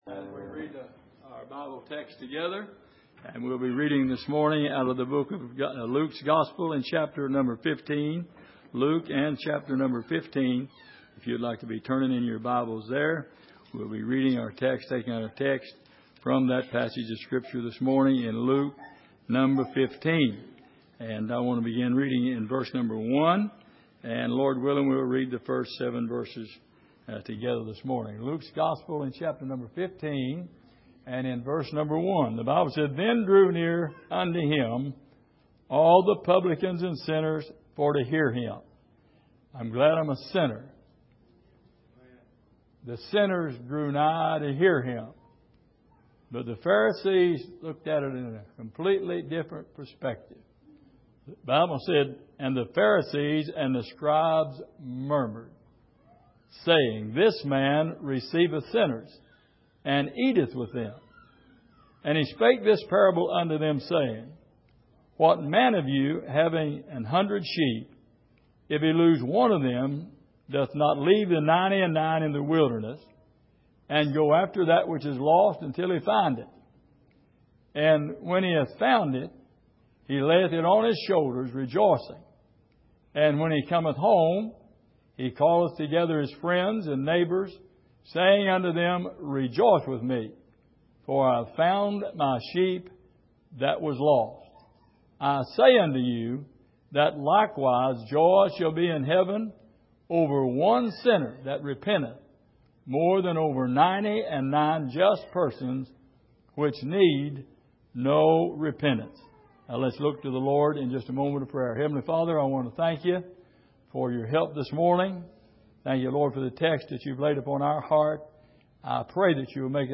Passage: Luke 15:1-7 Service: Sunday Morning